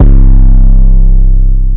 808 4 [ what ].wav